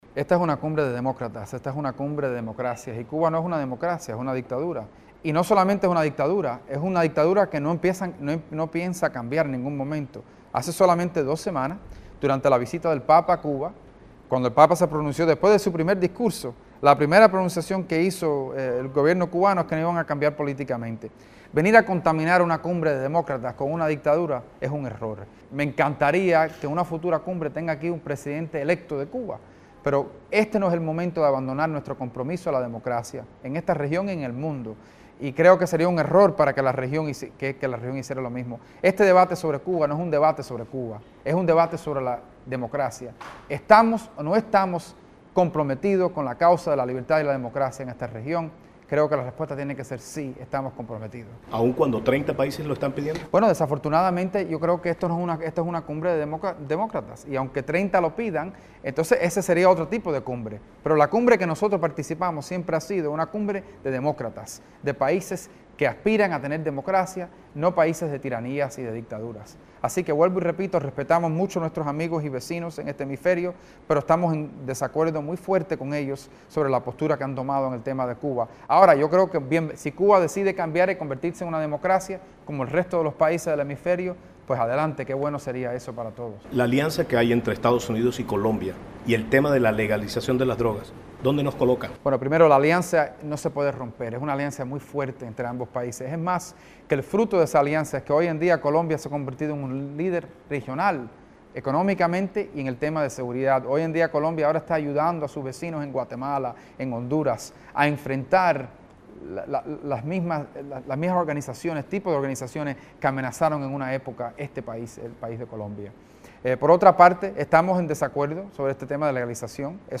Entrevista con el senador republicano por Florida, Marco Rubio.